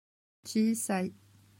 Ecoutez comment on dit certains mots de l’histoire en japonais: